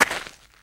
STEPS Gravel, Walk 07.wav